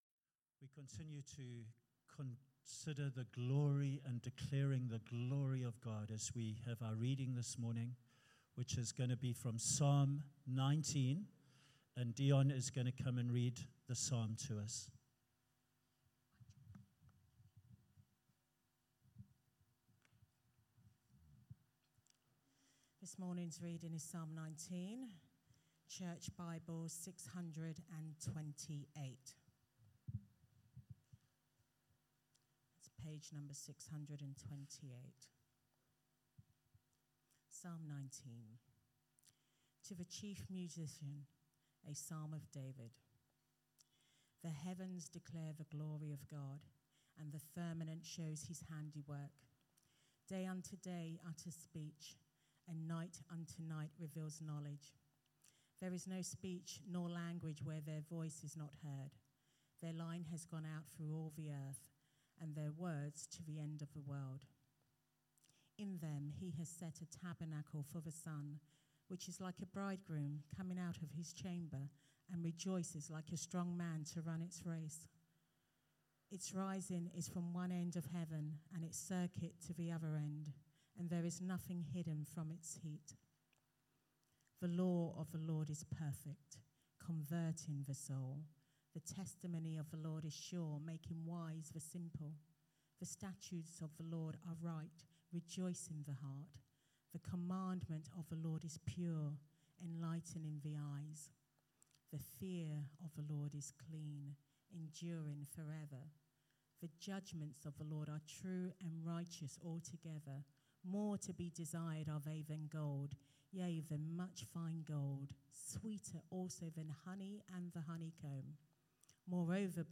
A Sunday sermon